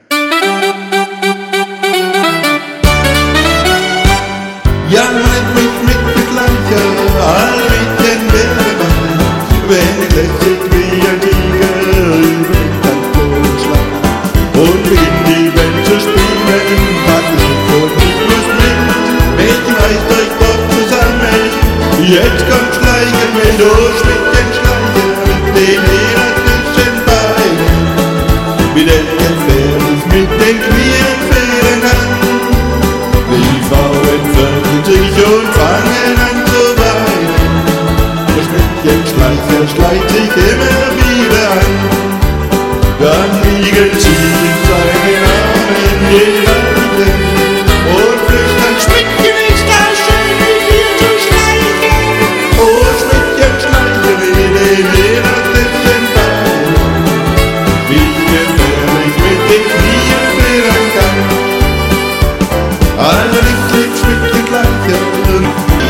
Die Aufnahmen stammen noch aus dem Jahr 2012 - aufgenommen auf meinem alten Tyros 2 Keyboard von Yamaha nach dem Motto: